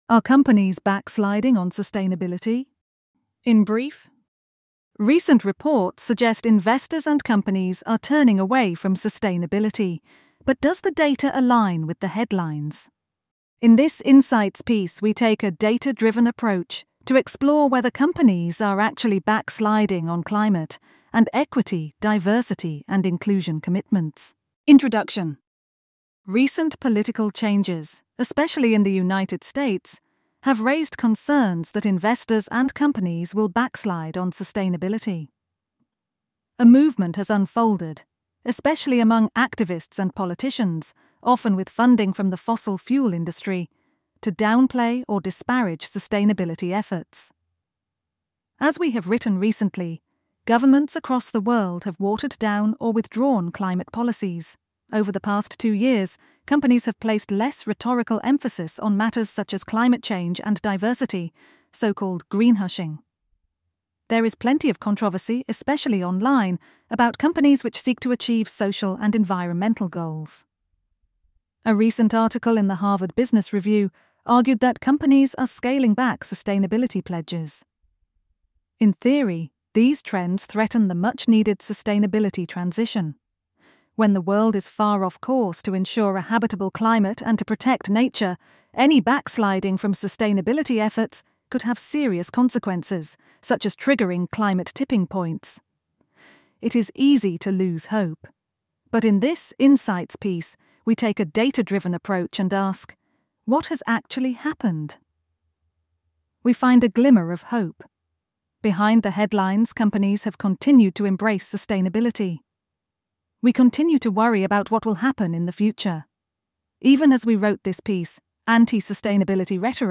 genim-backsliding-report-ai-adela-vo-v21.mp3